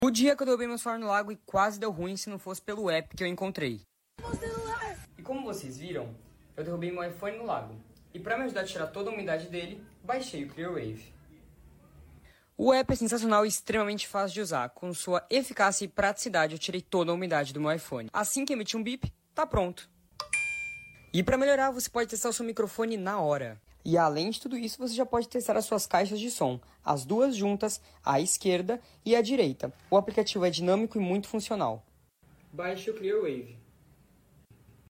📱💦 Expulsar Agua del Móvil sound effects free download